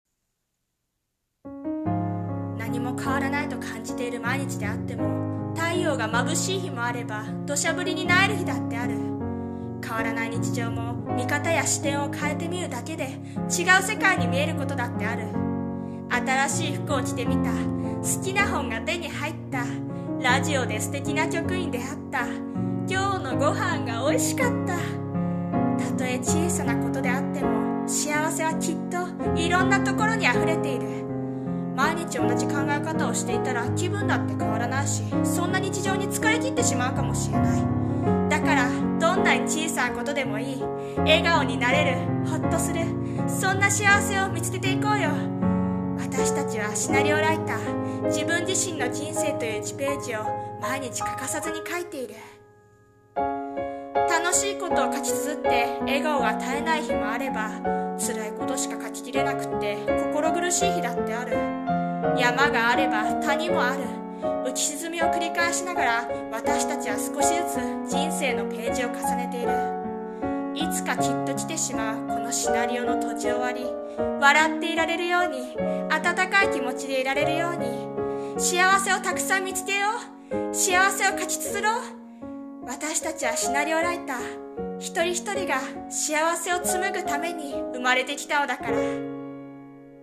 さんの投稿した曲一覧 を表示 【朗読台本】シナリオライター 〜幸せを紡ぐ者〜